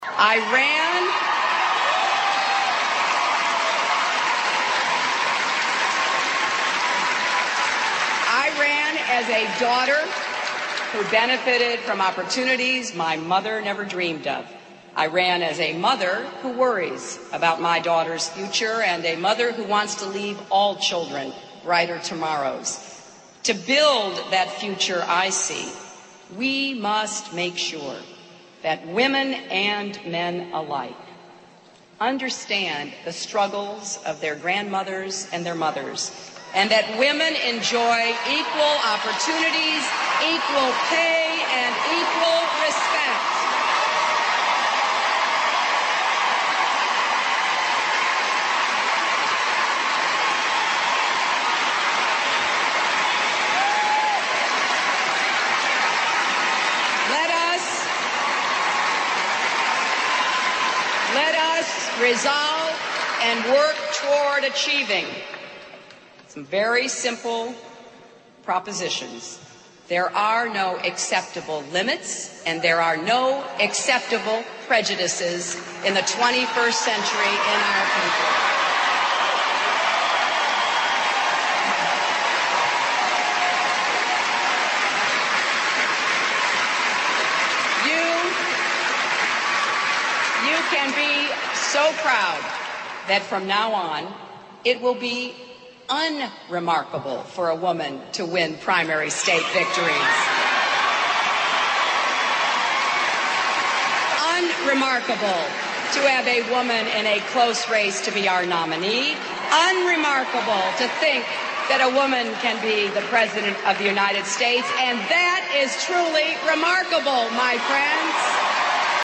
名人励志英语演讲 第77期:我放弃了 但我会继续战斗(11) 听力文件下载—在线英语听力室